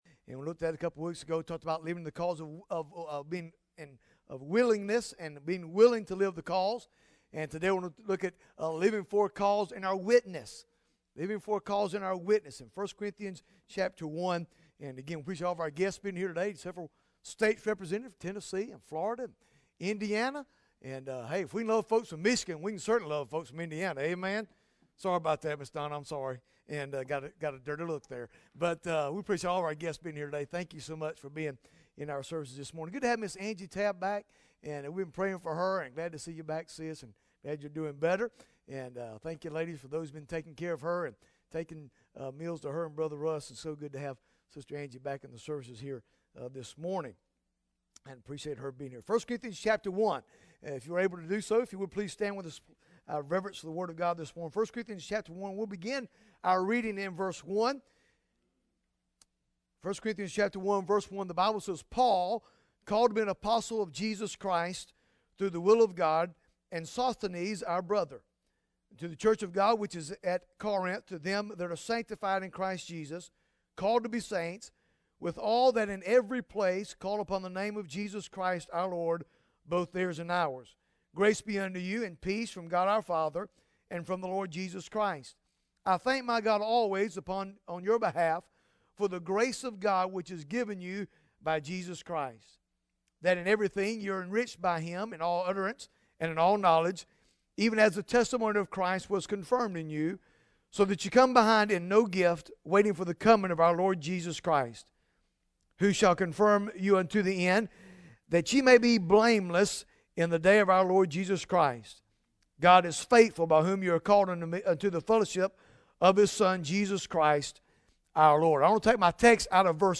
Bible Text: I Corinthians 1 | Preacher